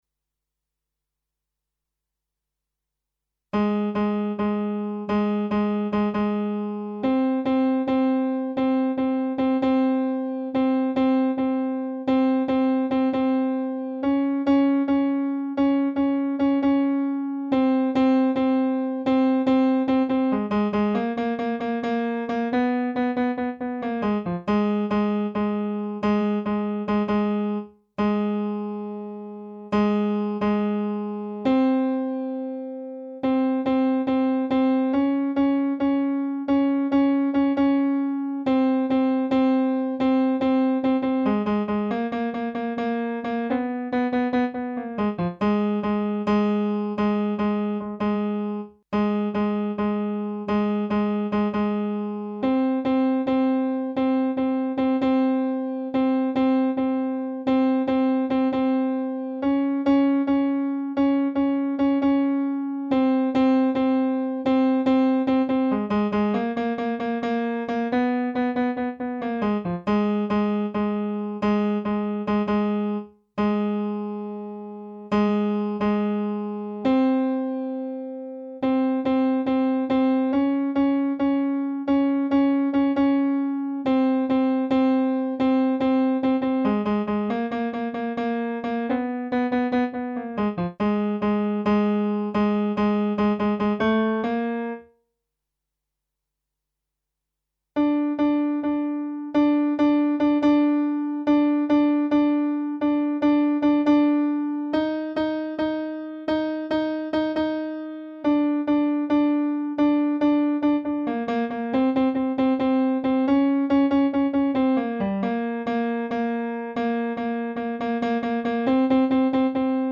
Barbar_Ann-.-Tenor_1.mp3